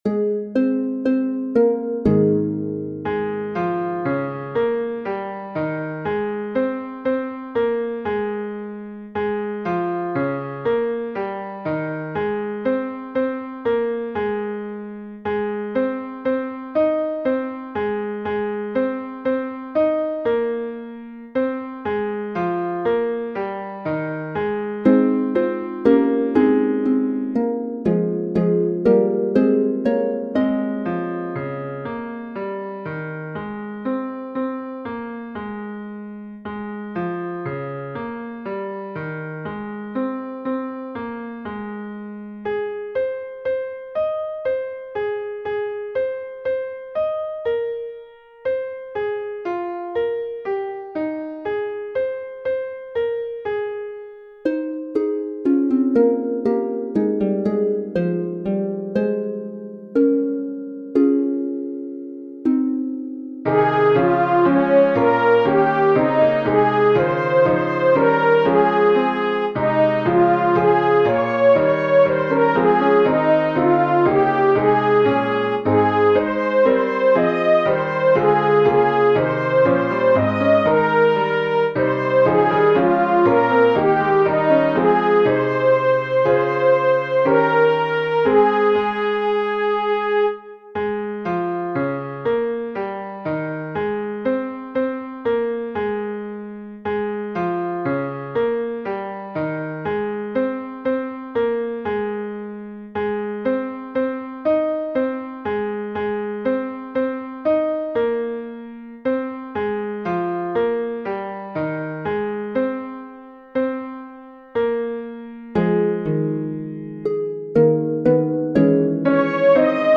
Only the third verse is sung in 4-part harmony. Other verses are unison singing.
The featured voice is a horn in verse 3.
immortal_invisible-soprano.mp3